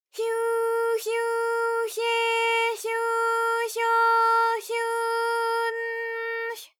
ALYS-DB-001-JPN - First Japanese UTAU vocal library of ALYS.
hyu_hyu_hye_hyu_hyo_hyu_n_hy.wav